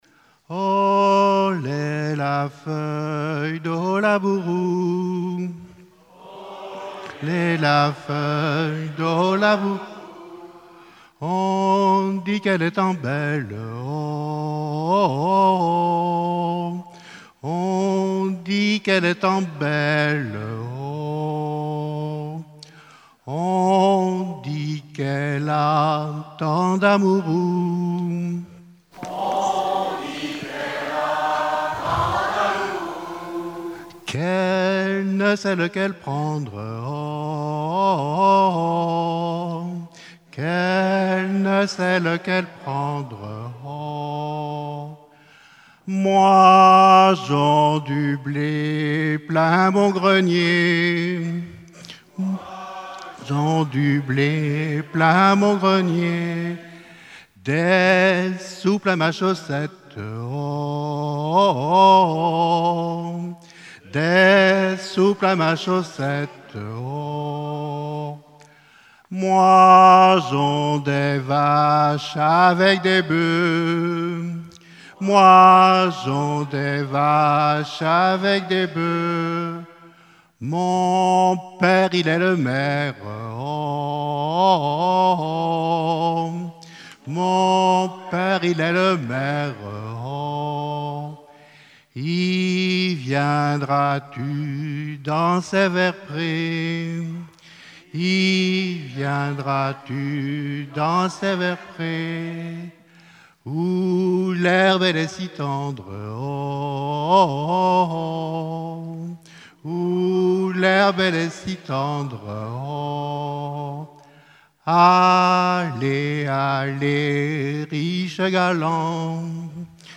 Festival de la chanson traditionnelle - chanteurs des cantons de Vendée
Pièce musicale inédite